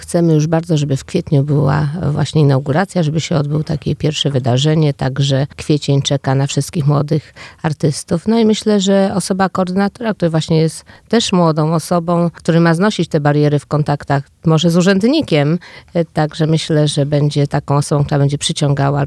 Pomysł zrodził się w odpowiedzi na potrzeby uzdolnionej młodzieży – mówiła na naszej antenie Ewa Sowa, zastępca Prezydenta Stargardu.